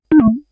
sound_menu_cancel.wav